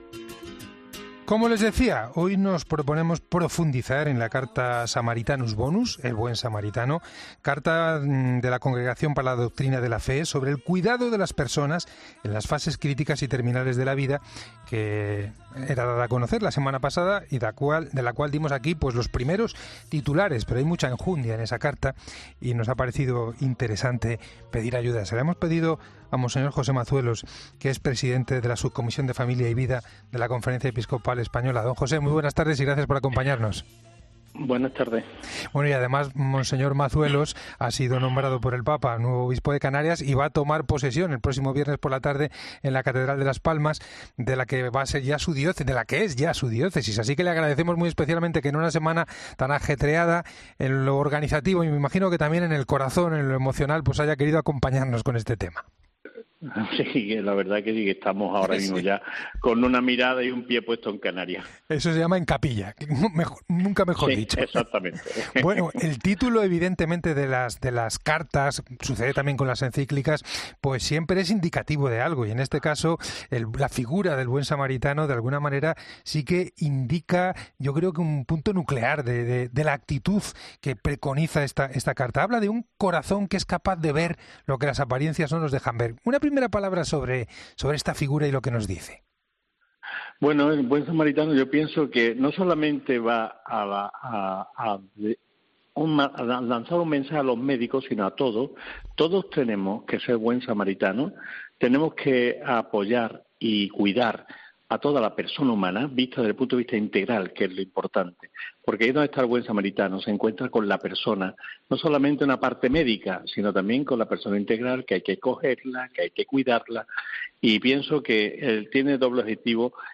La carta el Buen Samaritano, redactada por la Congregación para la Doctrina de la Fe, habla sobre el cuidado de las personas en las fases terminales de la vida. Mons. José Mazuelos, presidente de la Sucomisión de Familia y Vida de la Confernecia Episcopal y nuevo obispo de canarias profundiza en este texto y en su mensaje en El Espejo de la Cadena COPE.